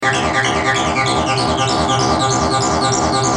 Sons Roland Tb303 -3
Basse tb303 - 50